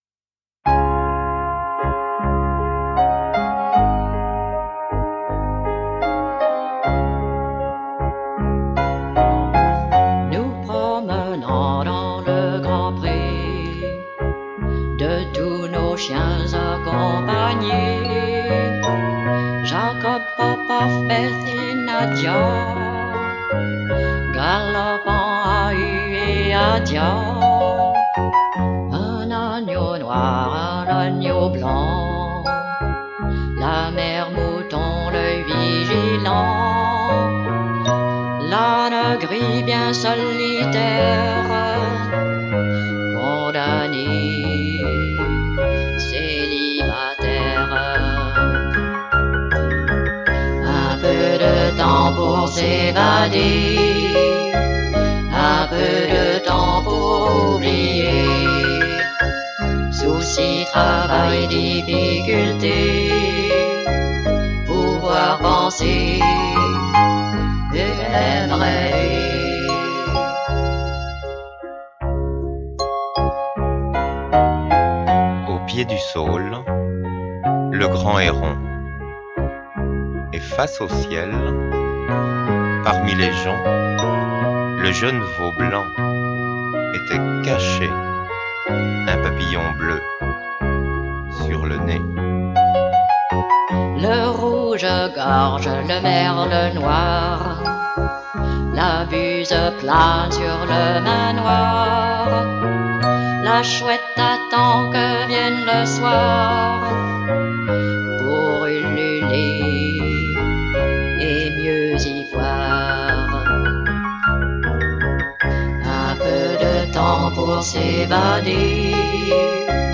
Enregistrés dans un studio de la région parisienne
qui tient les guitares et la 2éme voix.
Voix parlée